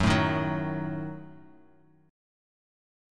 Gbm6 chord